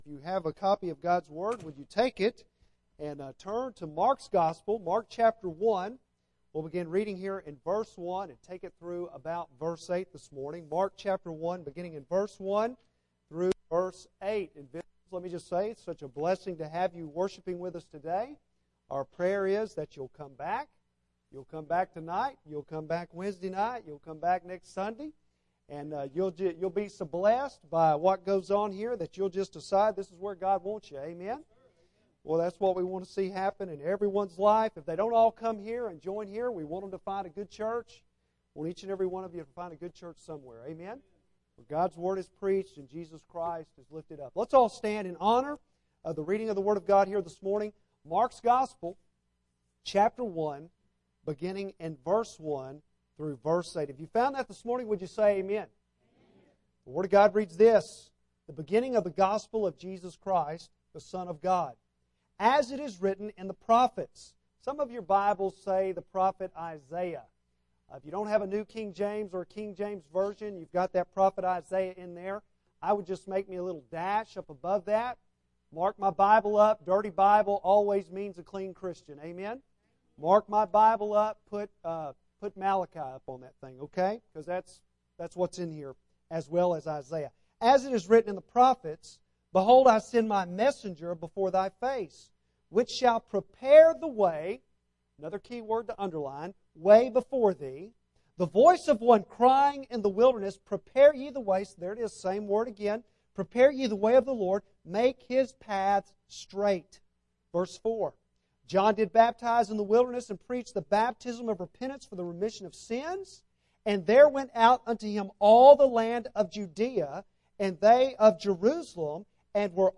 Bible Text: Mark 1:1-8 | Preacher